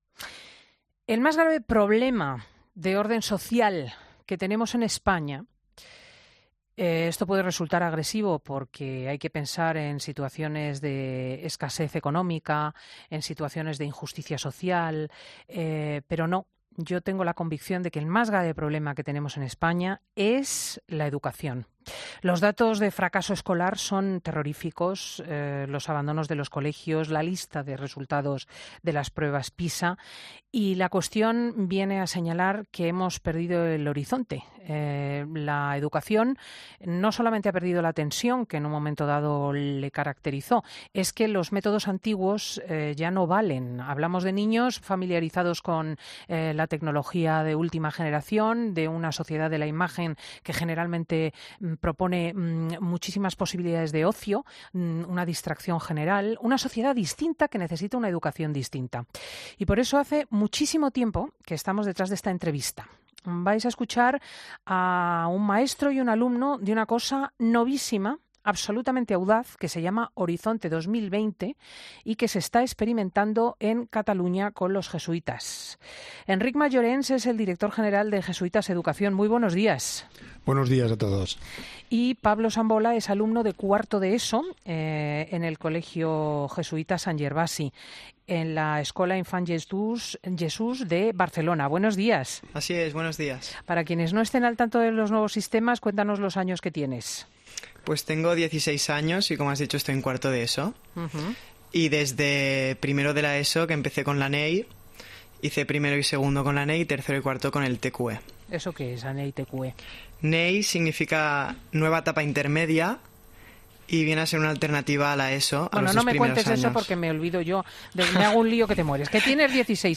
En el programa 'Fin de Semana' de COPE nos hemos aproximado este sábado a un revolucionario método educativo que están llevando a cabo los jesuitas en Cataluña. El proyecto se llama Horizonte 2020 y nace, según cuentan, de la reflexión sobre los objetivos de la educación actual.